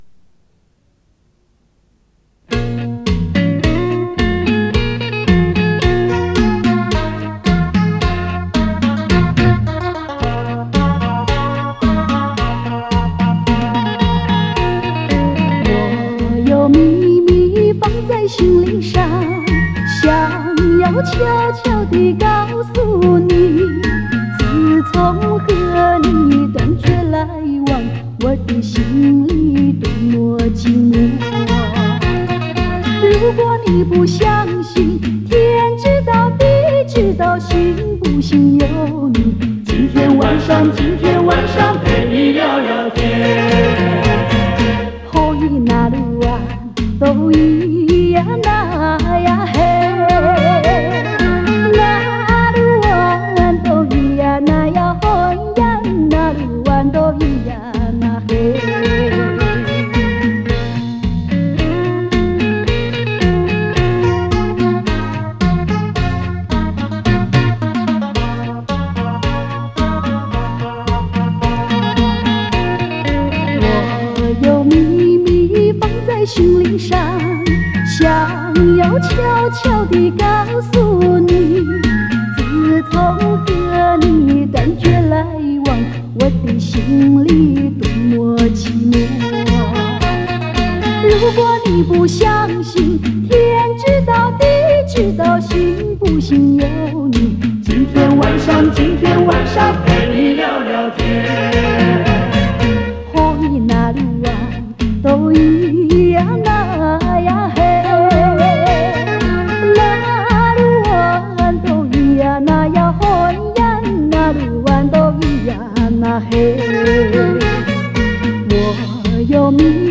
磁带数字化：2022-12-13